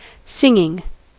Ääntäminen
Vaihtoehtoiset kirjoitusmuodot (rikkinäinen englanti) singin' Synonyymit sing Ääntäminen US : IPA : [ˈsɪŋ.ɪŋ] Haettu sana löytyi näillä lähdekielillä: englanti Singing on sanan sing partisiipin preesens.